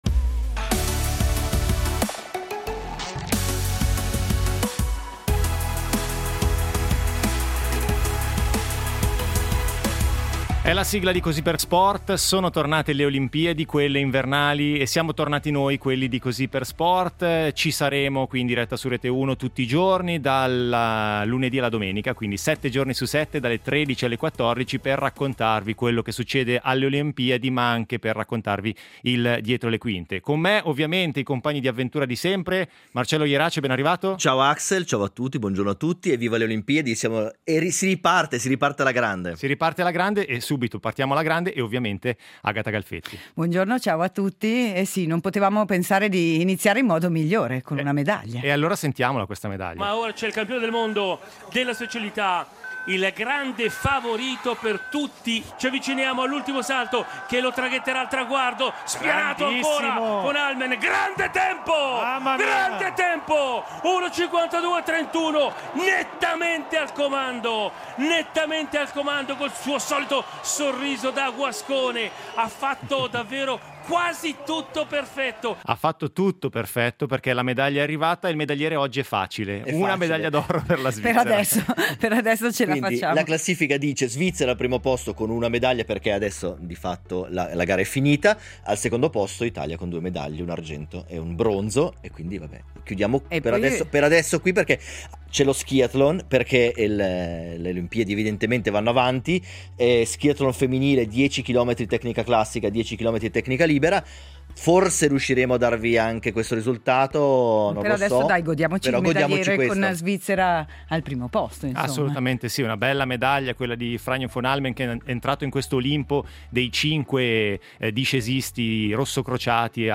In diretta